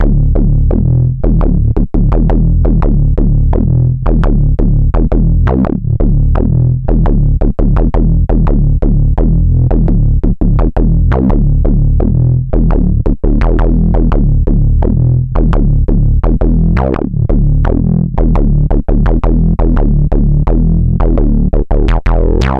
Class: Synth Module
Synthesis: hybrid